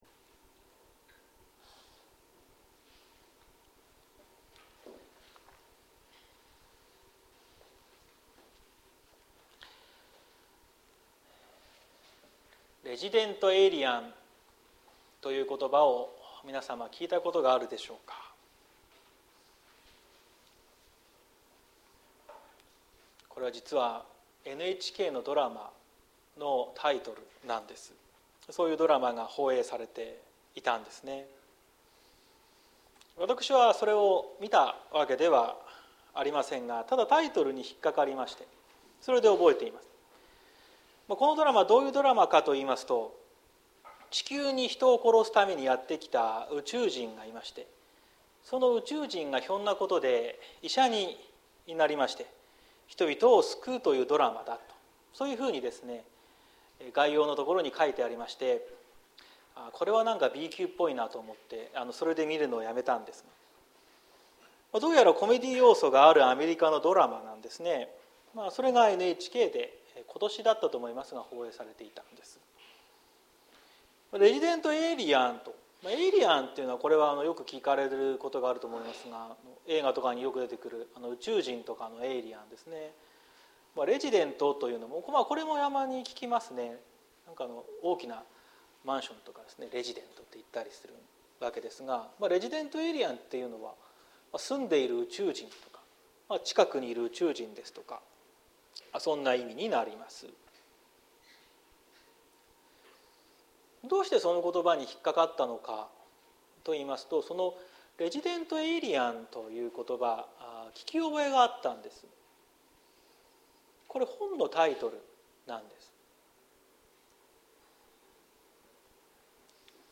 2023年10月08日朝の礼拝「本当に恐るべきお方」綱島教会
説教アーカイブ。